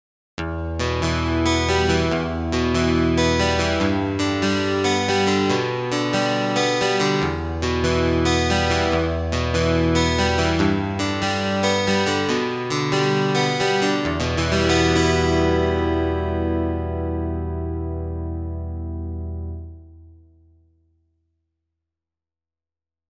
This library contains four program sound powered by detailed and realistic recordings of an Acoustic Guitar assembled into a single multisample.